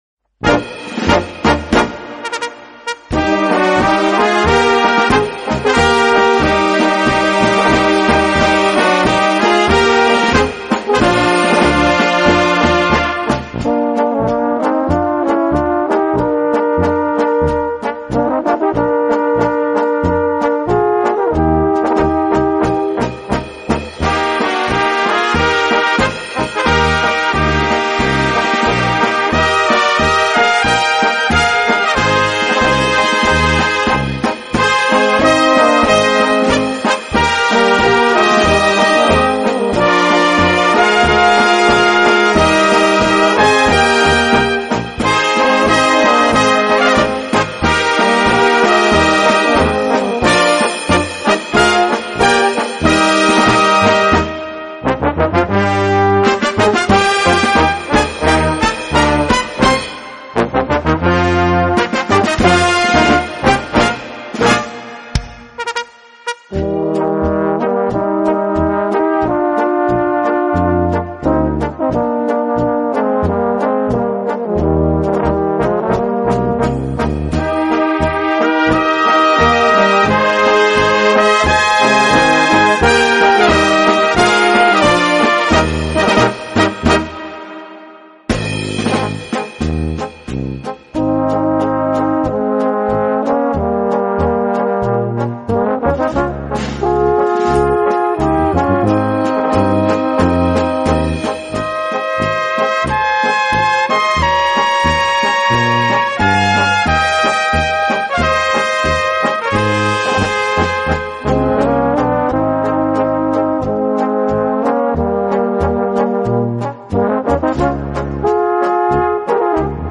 Gemütliche, feine Polka
Kleine Blasmusik-Besetzung PDF